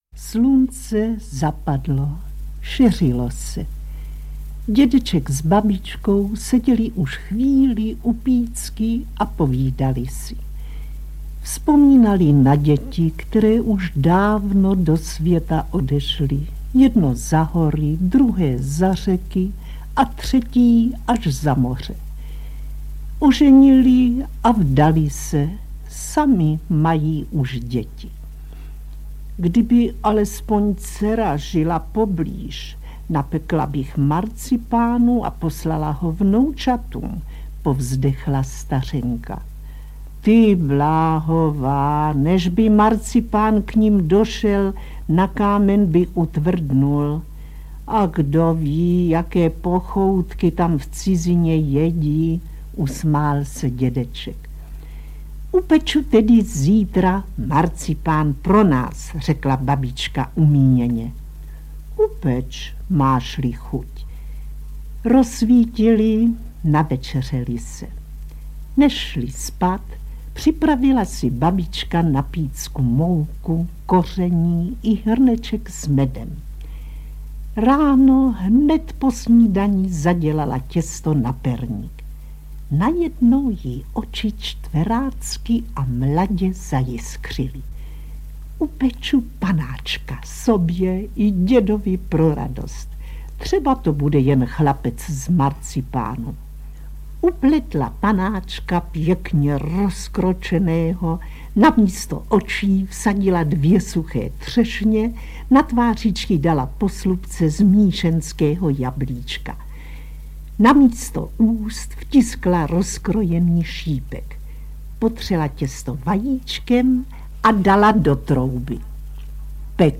Národní umělkyně Otýlie Beníšková vypravuje pohádky (Marcipánek / Sůl nad zlato) - Julie Brožová - Malá - Audiokniha
Národní umělkyně Otýlie Beníšková vypravuje pohádky (Marcipánek / Sůl nad zlato)
• Čte: Otýlie Beníšková